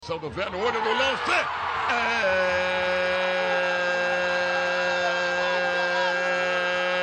silvio-luiz-narrando-varios-gols-pela-copa-paulista-rede-tv.mp3